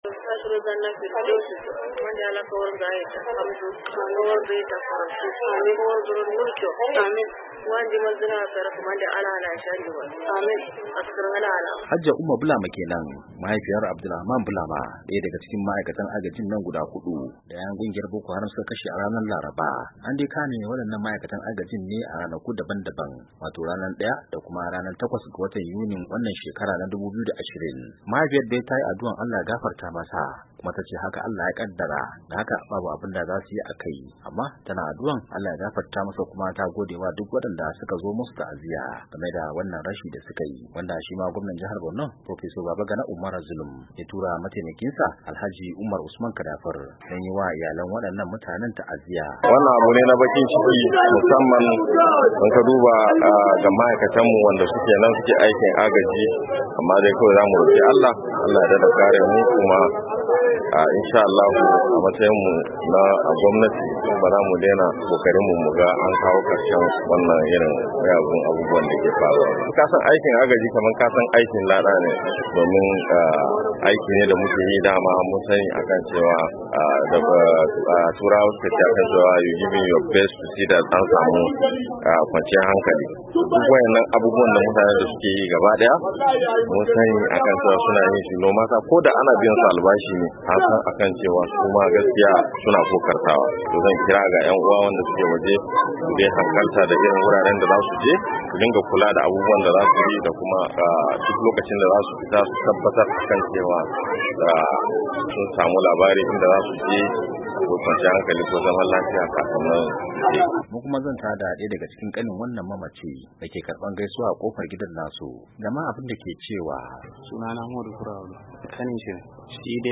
Rahoton kashe ma'aikatan jinkai-2:30"